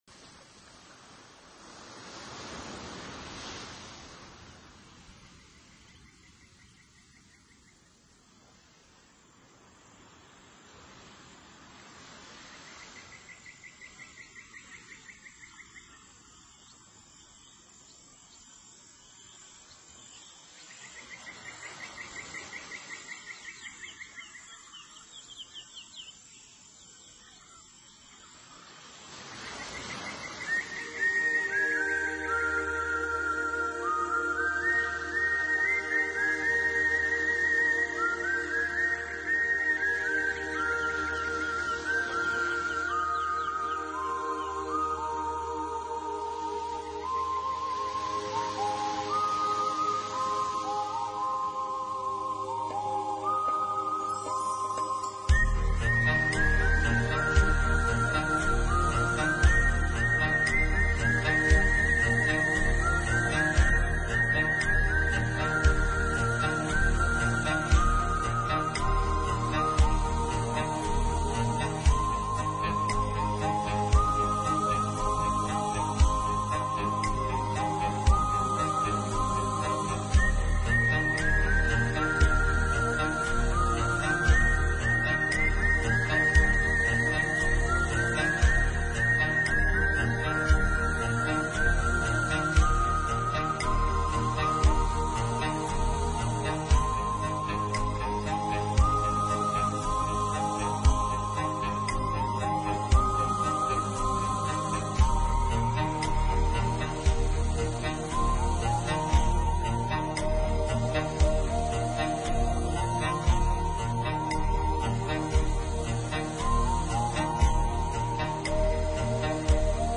音乐类型：NewAge 新世纪
音乐风格：New Age
专辑每首开头和结尾都有一段海洋的声音，或者鸟儿，鲸鱼的鸣叫！
木吉他，琴键为主乐器，长笛和排箫伴随的，优美而活泼，柔情而流畅的音乐作品！